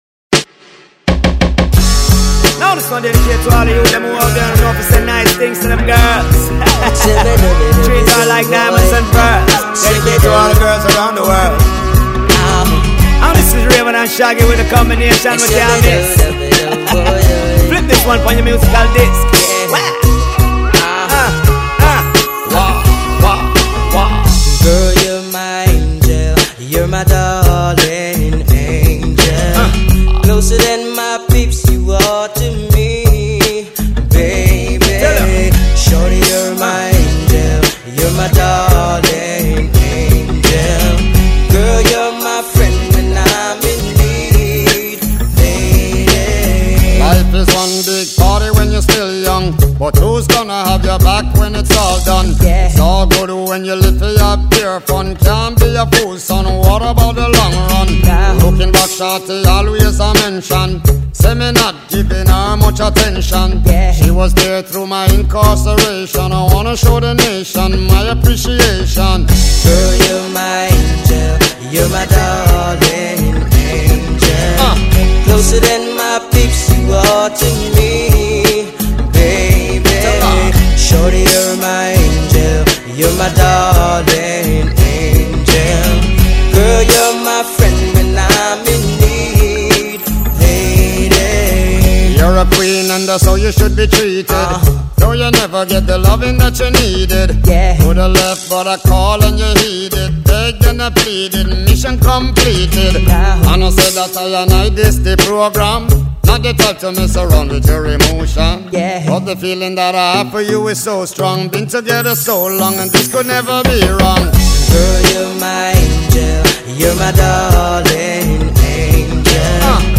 These are some of our favorite slow dance songs.